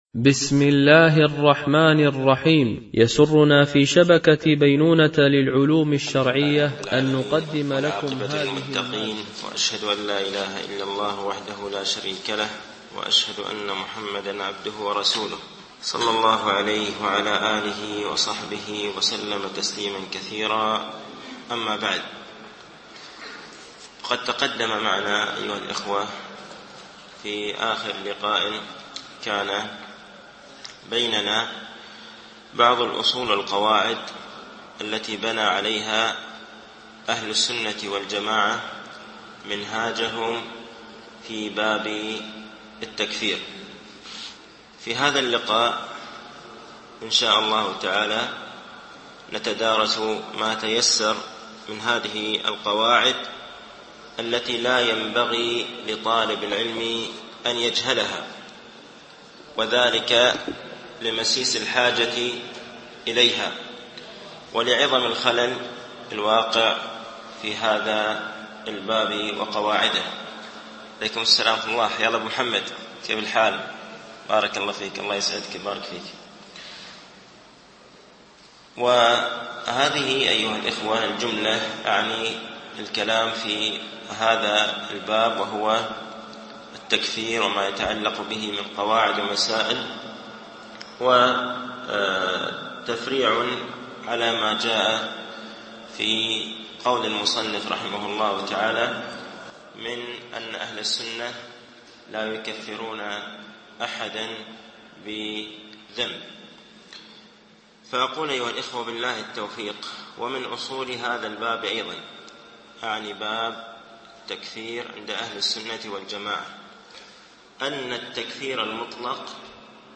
شرح مقدمة ابن أبي زيد القيرواني ـ الدرس السابع و الستون